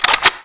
menu select.wav